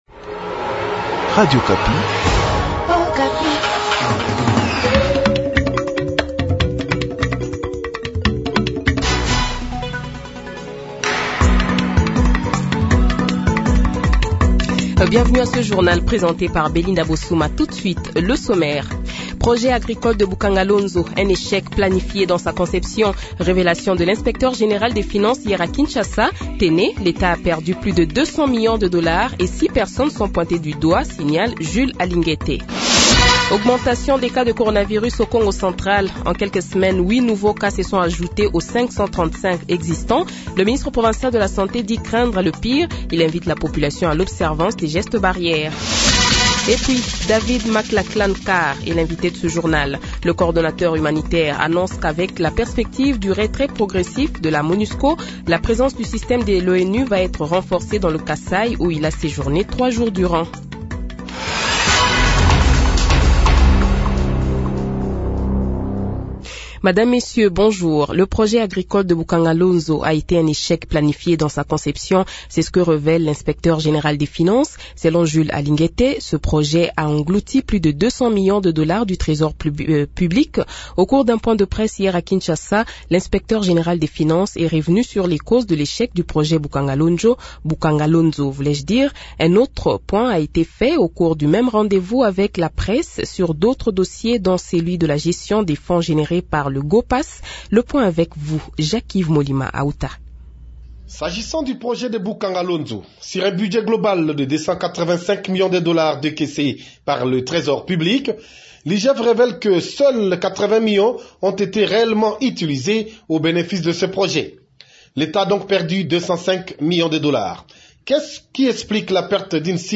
Journal Français Midi
Invité : KANANGA : David Mclachlan-Karr, coordonnateur humanitaire en Rdc